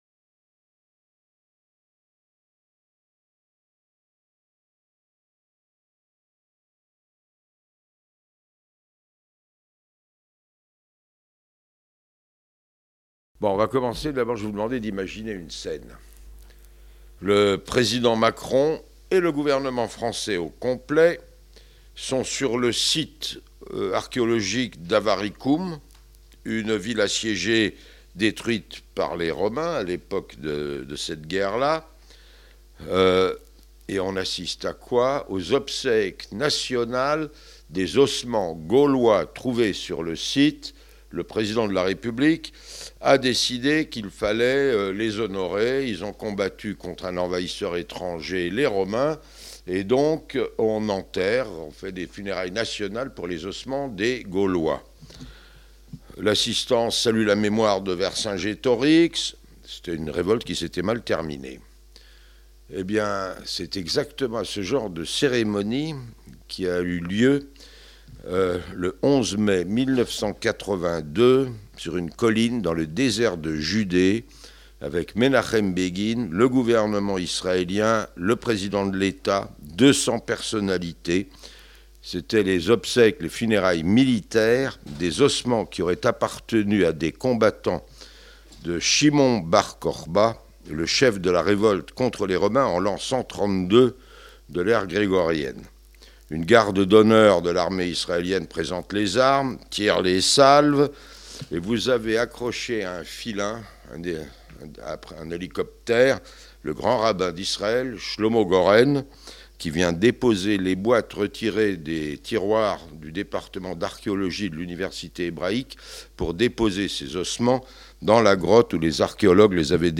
Conférences Penser global Correspondant de France Télévisions à Jérusalem de 1981 à 2015, Charles Enderlin a été le témoin privilégié de moments historiques du conflit israélo-palestinien.